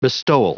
Prononciation du mot bestowal en anglais (fichier audio)
Prononciation du mot : bestowal